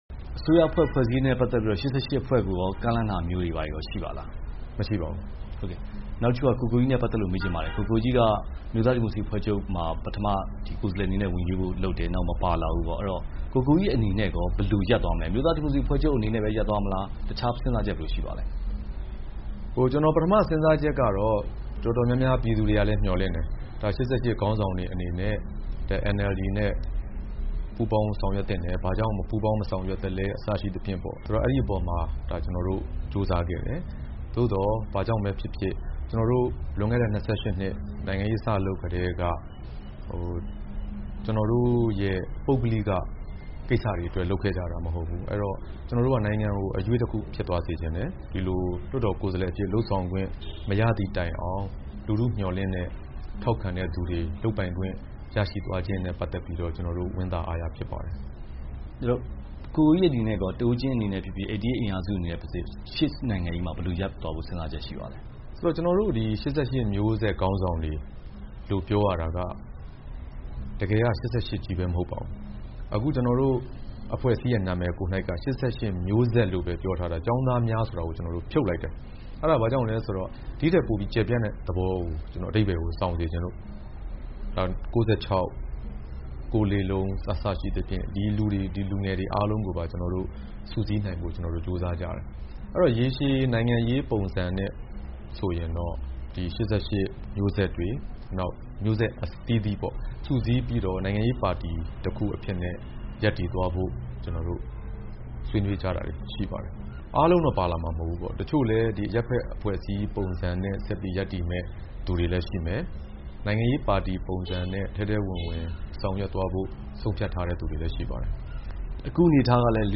၈၈ မျိုးဆက် အင်အားစုတွေအနေနဲ့ နိုင်ငံရေးပါတီတစ်ခု တည်ထောင်သွားဖို့ ရှိတယ်လို့ ကိုကိုကြီးက ဗွီအိုအေ မြန်မာပိုင်း နဲ့ တွေ့ဆုံမေးမြန်းရာ မှာ ပြောလိုက်ပါတယ်။ အတိုက်အခံ ပါတီတစ်ရပ်အနေနဲ့ အစိုးရရဲ့ လုပ်ဆောင်ချက် နဲ့ ပတ်သက်ပြီး၊ မှန်တာကို ထောက်ခံပြီး မှားတာကို ဝေဖန်သွားမှာ ဖြစ်တယ်လို့ လည်း ဆိုပါတယ်။